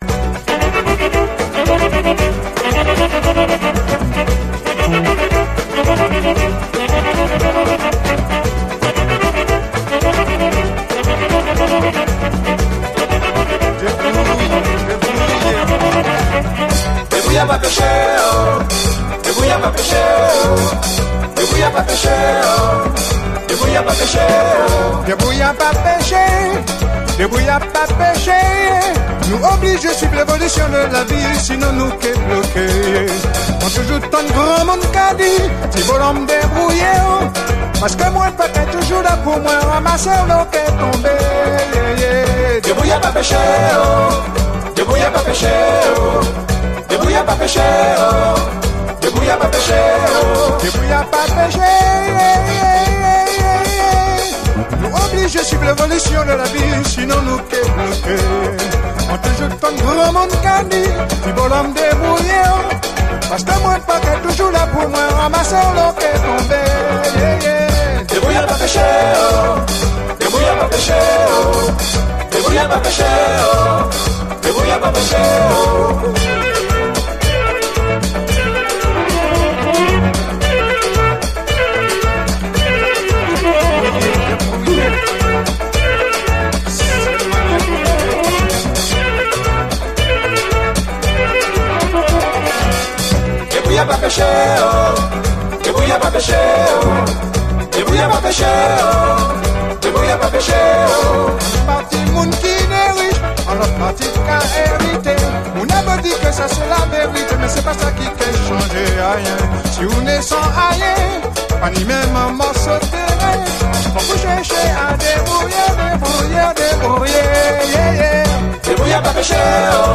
これでもかと甘酸っぱいメロディー・ラインが続く青春チューン
ダウンタウンへ繰り出したくなるシティー・ポップ的なイントロから心弾むハッピー・ソウル
ポップさ全開